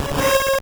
Cri de Migalos dans Pokémon Or et Argent.